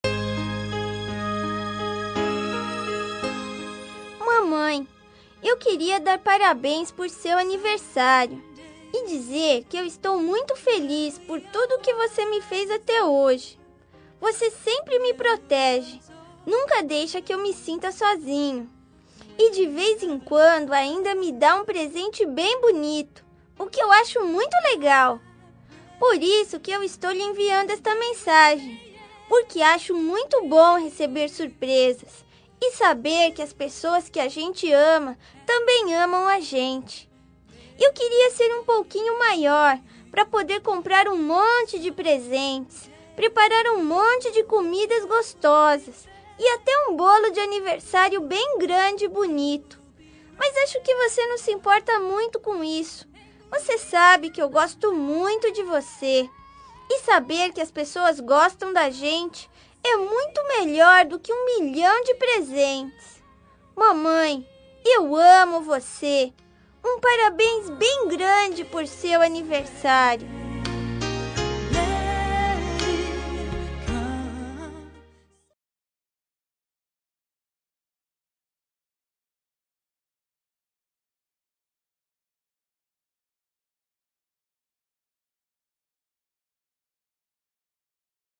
Aniversário Voz Infantil – Mãe – Voz Masculina – Cód: 257145